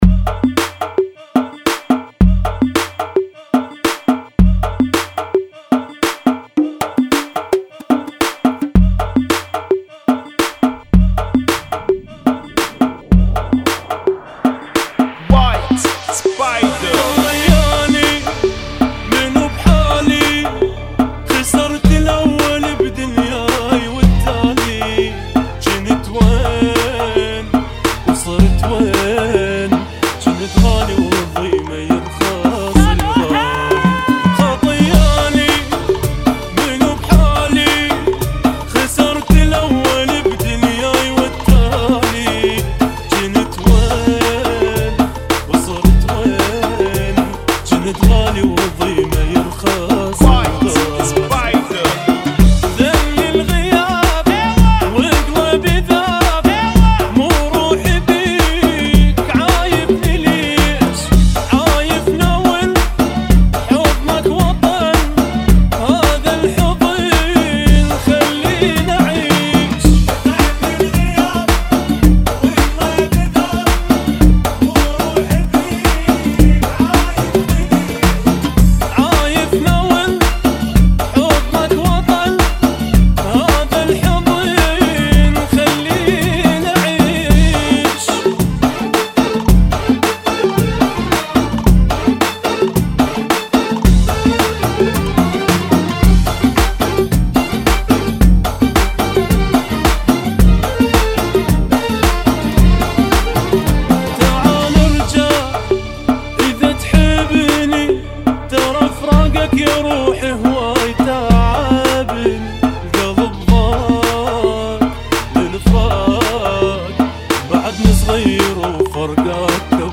110 Bpm
Funky